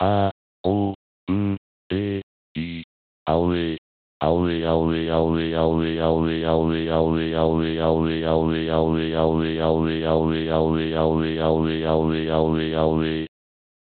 アオウエイ　　（音声読み上げソフト）
男声-1）
hz-aouei-mal-1.mp3